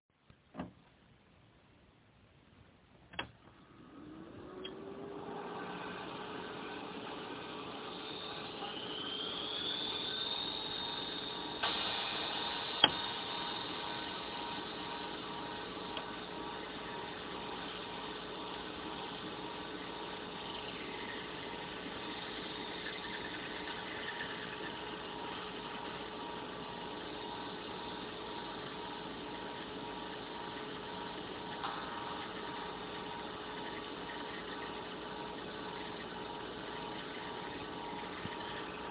Die Blöcke unter dem Monitor sind Festplatten, der obere eine Wechselfestplatte mit 44 MB. Läuft noch einwandfrei, ist nur etwas laut.
Die waren früher viel lauter, aber da ist bei der Wechselfestplatte auch ein Eiern dabei, das vielleicht nicht so gehört: